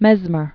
(mĕzmər, mĕs-), Franz 1734-1815.